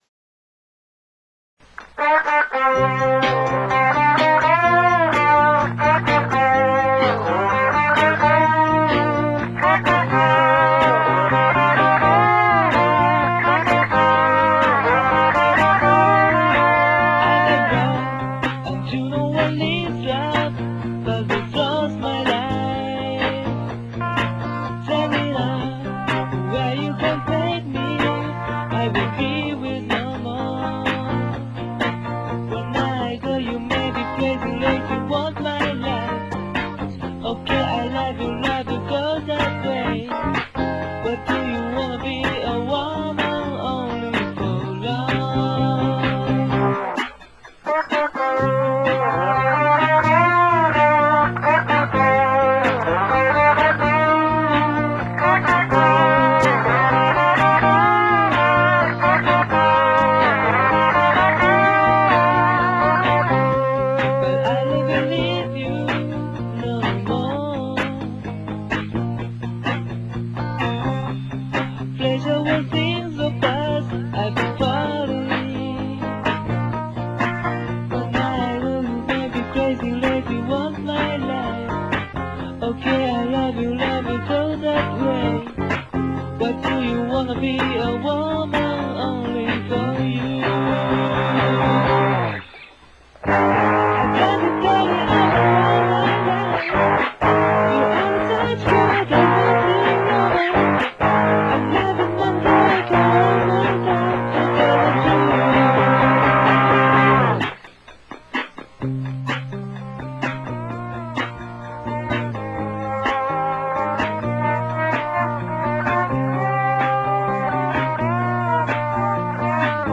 当時はまだCMX-1を持っていなかったので、ラジカセとカセットデッキを交互にピンポン録音するという時代でした。双方の回転スピードが微妙に違うので、10回くらい録音を繰り返すと半音以上音程が上がっていました・・ドラムはカシオトーンの8beatをそのまま。Bassは確かギターのチューニングを下げて録音したかな。